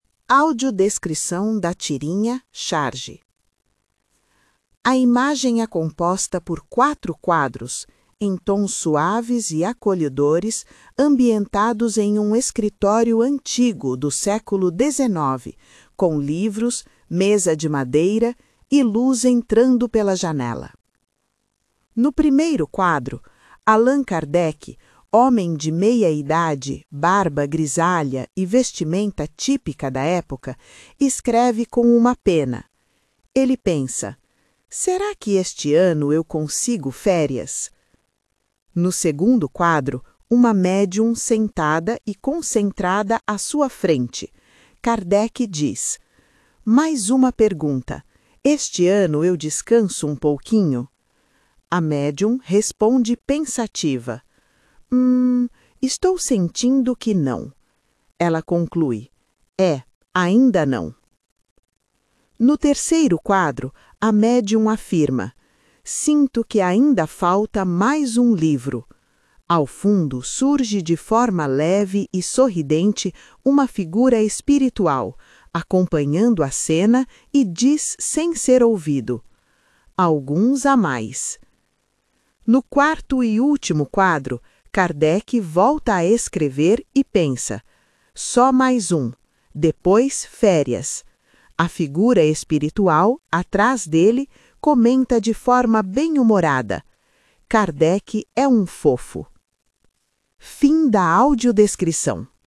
🦻 Áudio-descrição da tirinha